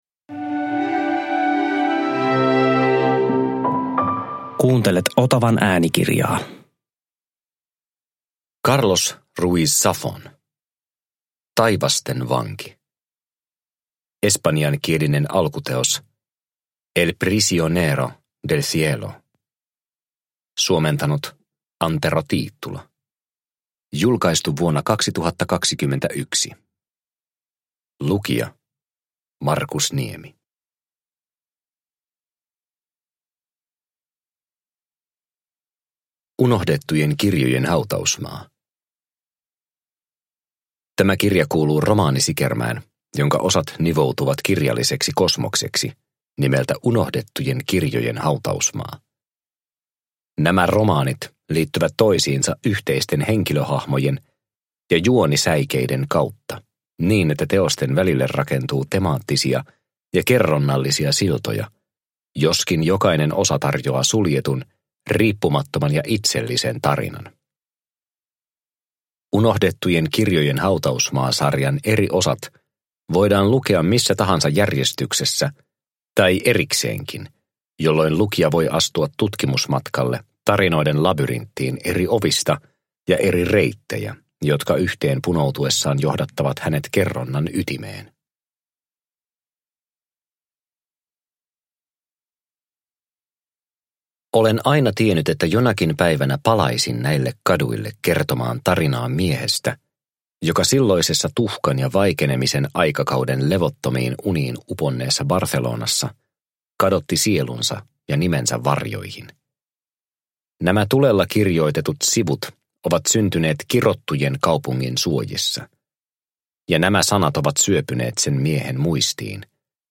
Taivasten vanki – Ljudbok – Laddas ner